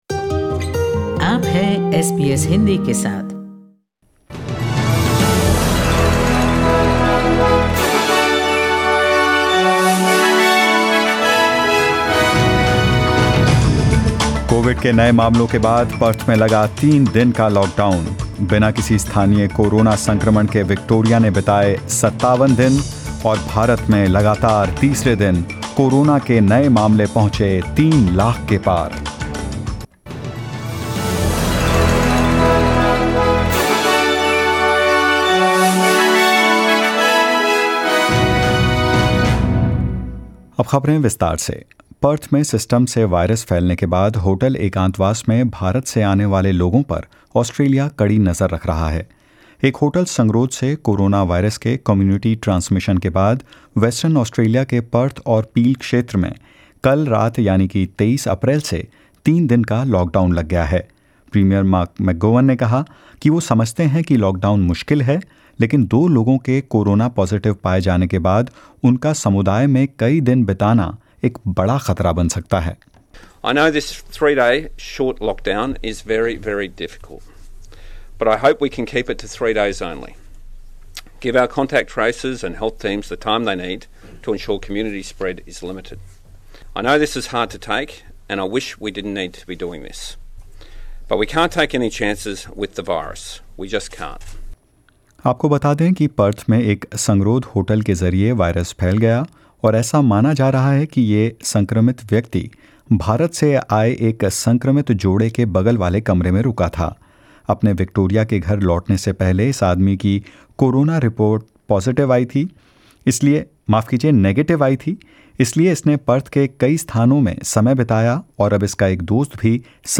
News in Hindi: Western Australian announces a three-day lockdown for Perth and Peele